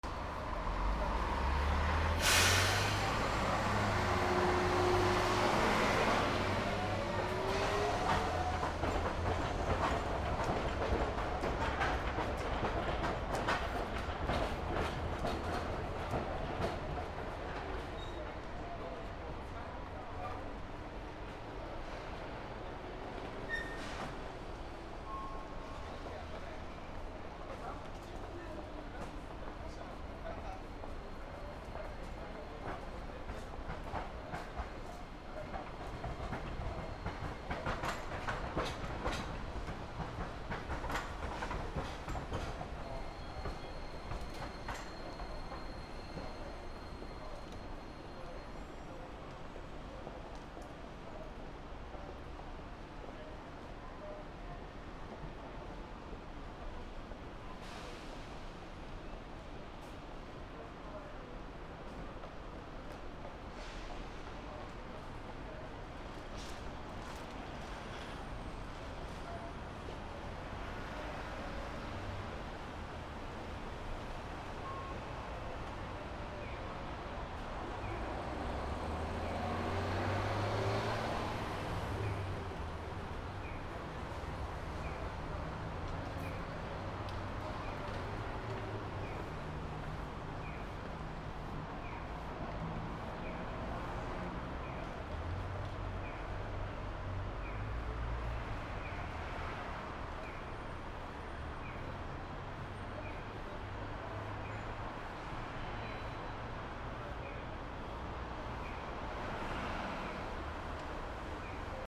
路面電車乗り場
広島 D50